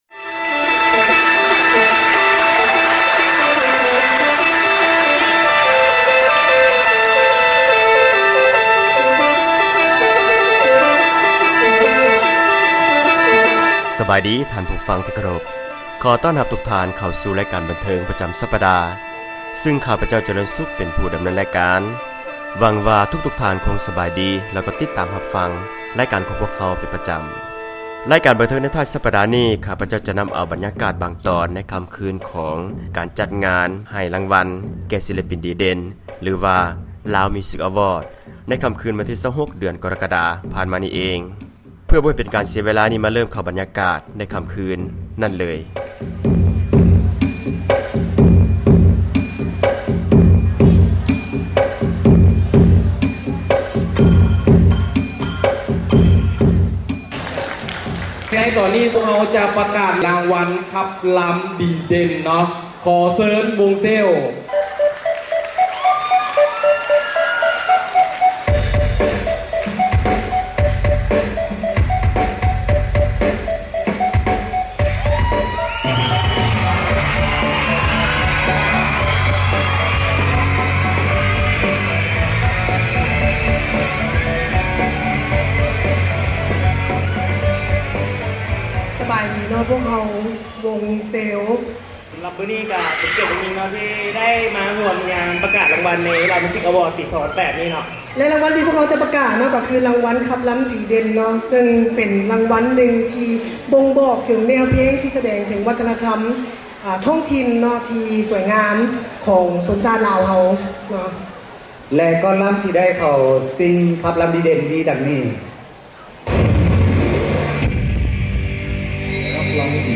ງານໃຫ້ລາງວັນ ສິລປິນດີເດັ່ນ ຫລື Lao Music Awards, ຈັດຂຶ້ນທີ່ ຫໍວັທນະທັມແຫ່ງຊາດ ໃນຄໍ່າຄືນ ວັນທີ 26 ກໍຣະກະດາ ປີ 2008.
ຣາຍການບັນເທີງ ໃນທ້າຍສັປດານີ້ ຂ້າພະເຈົ້າ ໄດ້ນໍາເອົາ ບັນຍາກາດ ບາງຕອນ ຂອງຄໍ່າຄືນ ງານໃຫ້ລາງວັນ ສິລປິນ ດີເດັ່ນ ຫລື Lao Music Awards ມາສເນີທ່ານ.